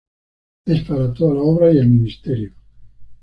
Ler máis (Inglés) ministry Frecuencia B2 Con guión como mi‧nis‧te‧rio Pronúnciase como (IPA) /minisˈteɾjo/ Etimoloxía (Inglés) Tomado de latín ministerium In summary Borrowed from Latin ministerium.